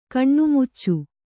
♪ kaṇṇumuccu